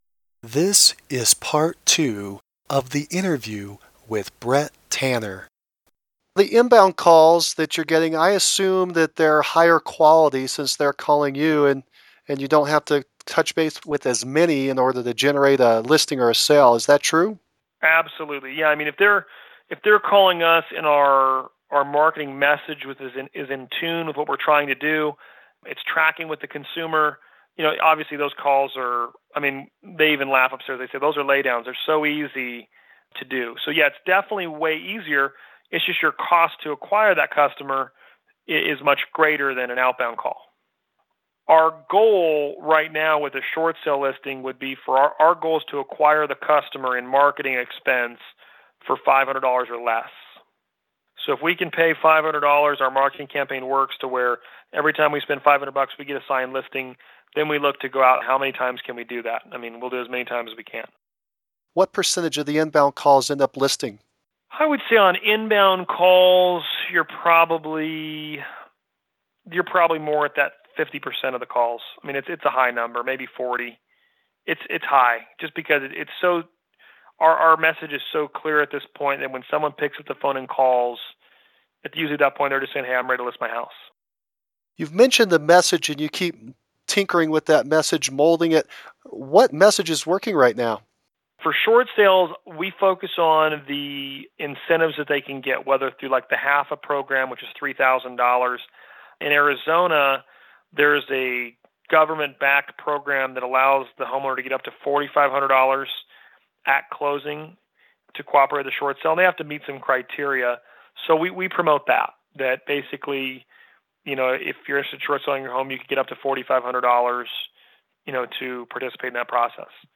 August 7, 2012 Top Agent Interview with